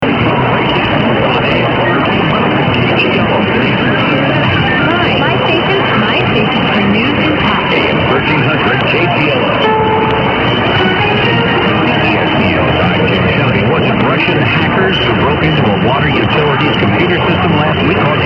A FEW AUDIO CLIPS OF RECENT RECEPTION: